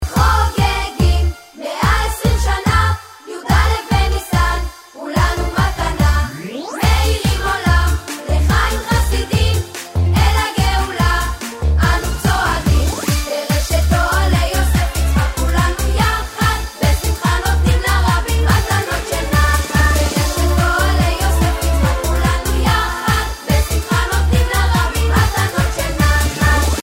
ג'ינגל מקוצר לצלצול